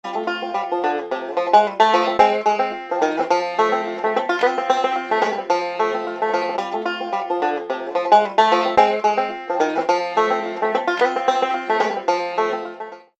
Короткая мелодия